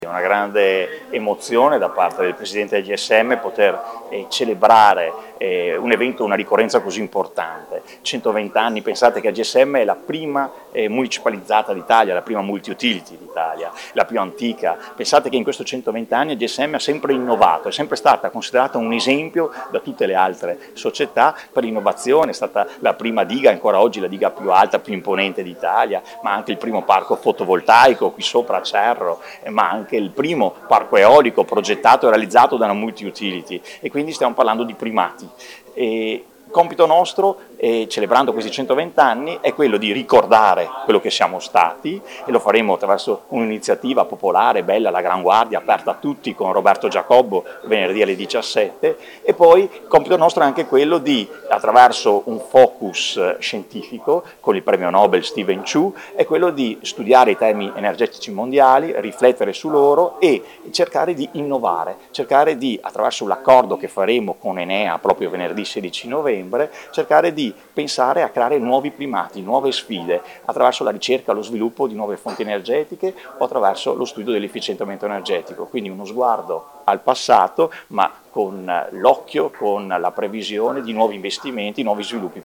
Al microfono del nostro corrispondente